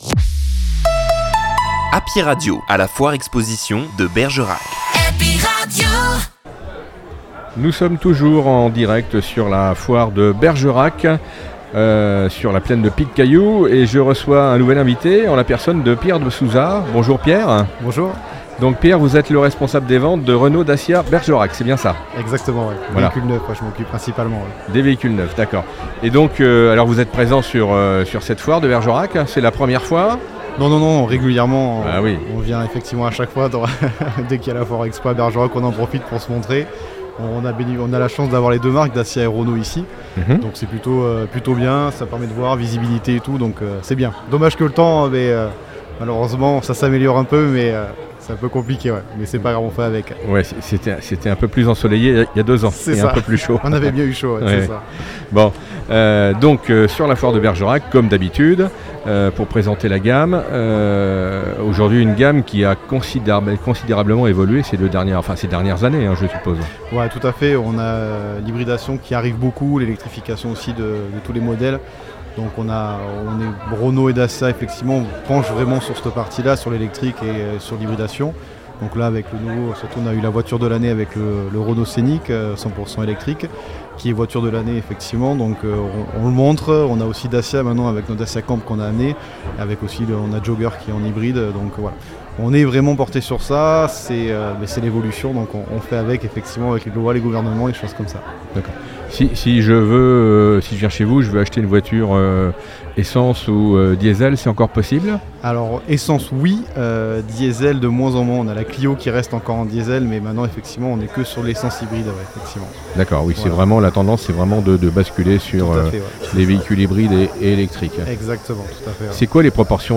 Foire Expo De Bergerac 2024